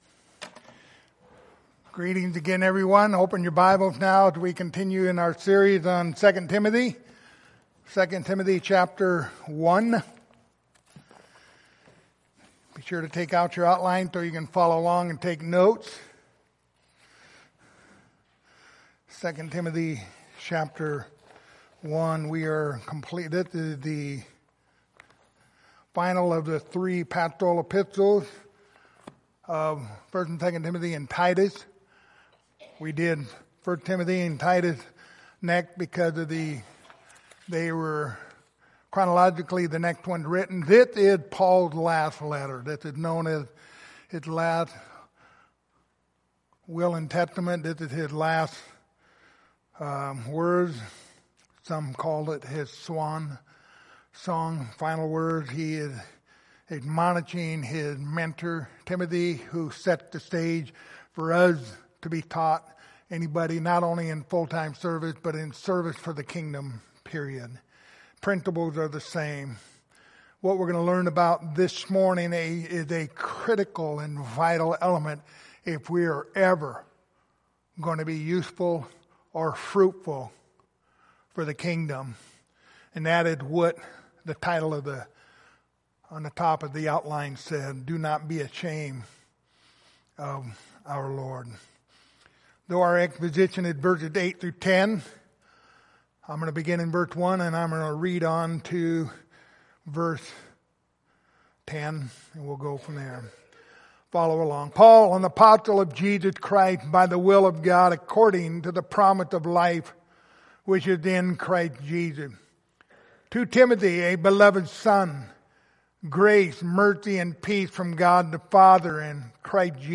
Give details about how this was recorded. Passage: 2 Timothy 1:8-10 Service Type: Sunday Morning